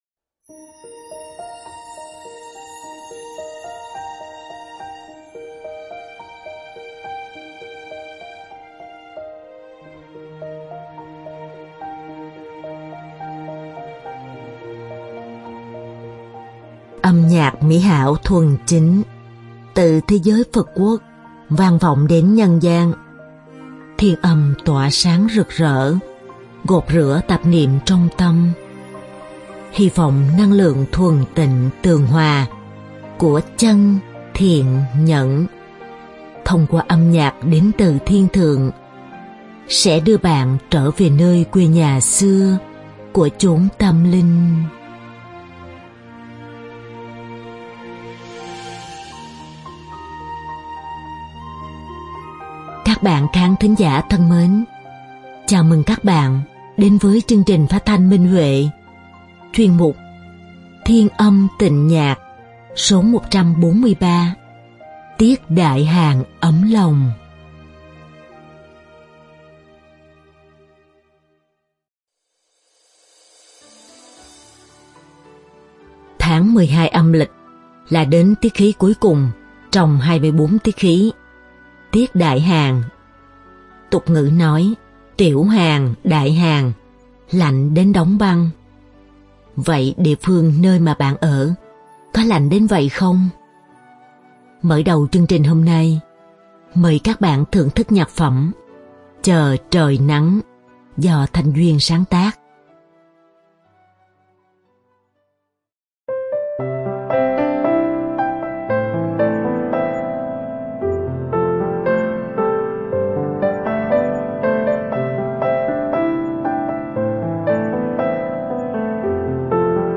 Đơn ca nam
Hợp xướng